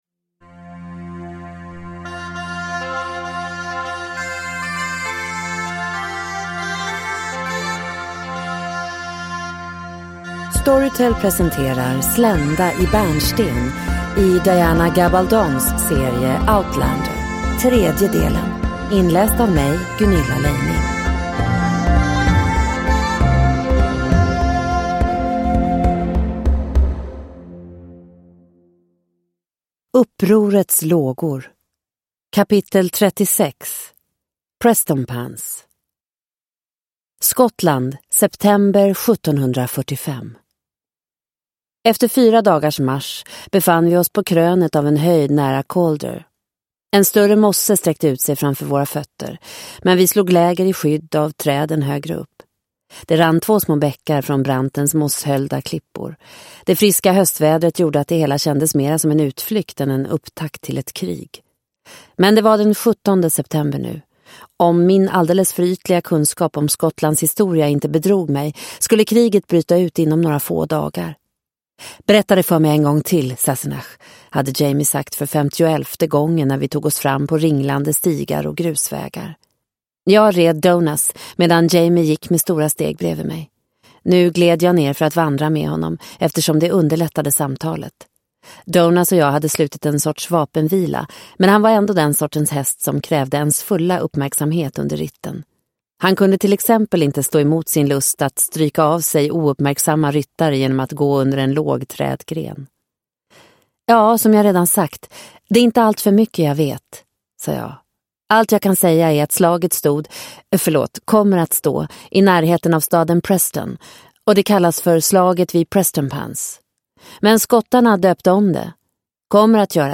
Slända i bärnsten - del 3 – Ljudbok – Laddas ner